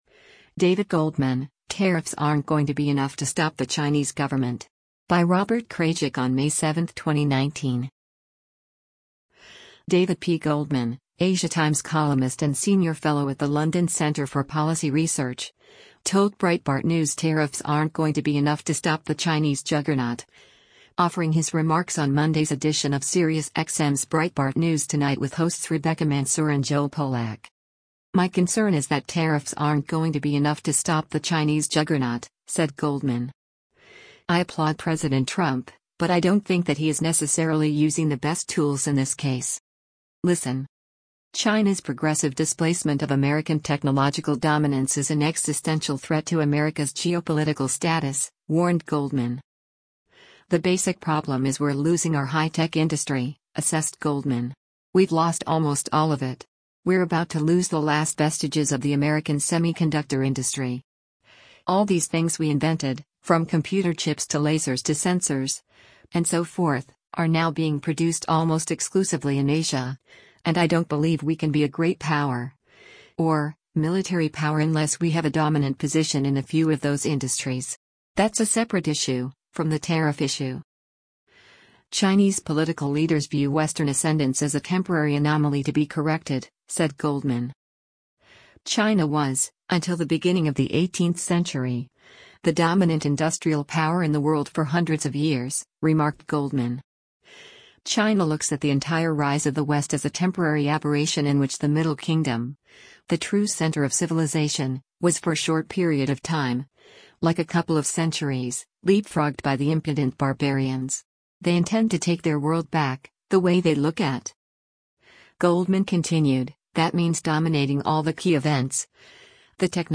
Breitbart News Tonight broadcasts live on SiriusXM Patriot channel 125 weeknights from 9:00 p.m. to midnight Eastern or 6:00 p.m. to 9:00 p.m. Pacific.